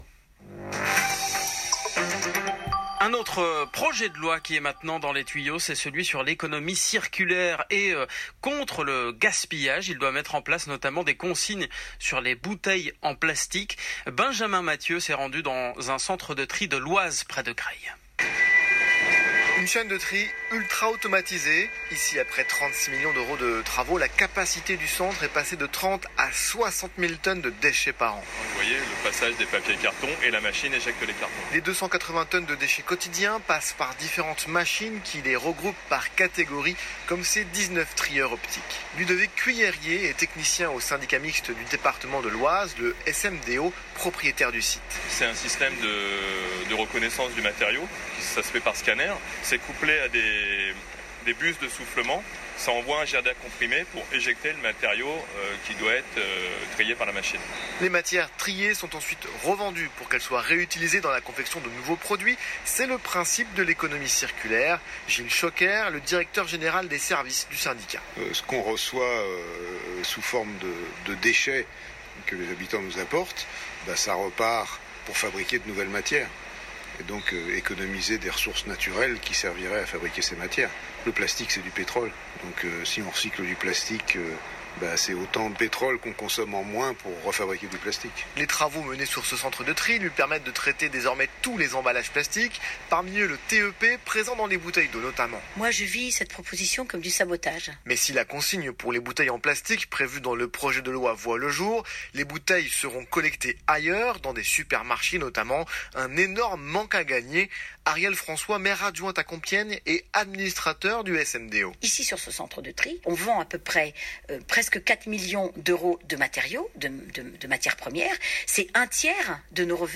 Consigne sur les bouteilles en plastique : reportage au sein de notre centre de tri
Suite au projet de loi relatif à la lutte contre le gaspillage et à l’économie circulaire présenté mercredi 10 juillet en conseil des ministres, France Info s’est rendu dans notre nouveau centre de tri de collectes sélectives de Villers-Saint-Paul pour recueillir l’impression de nos techniciens et de nos élus sur la mise en place de la consigne sur les bouteilles en plastique.
Reportage-Centre-de-Tri.mp3